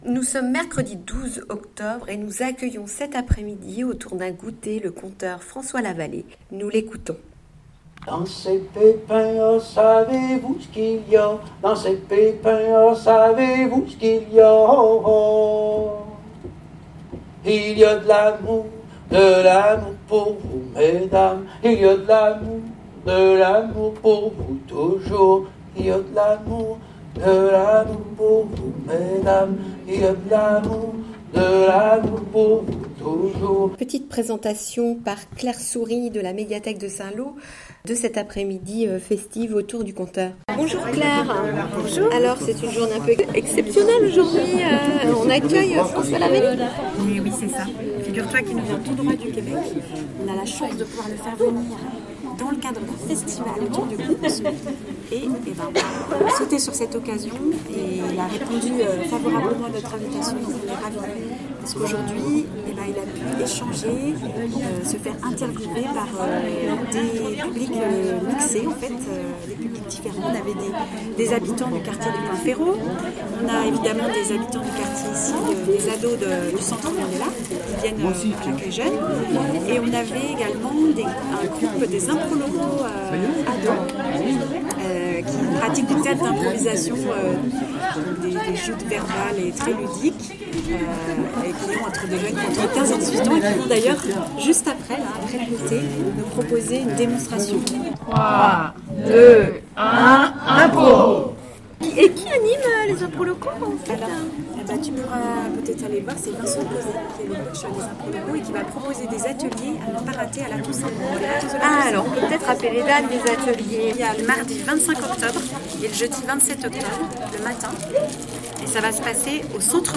Ecoutons les questions des personnes présentes...
Echanges avec le conteur autour d'un gôuter ..